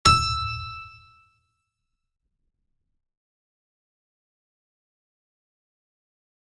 piano-sounds-dev
e5.mp3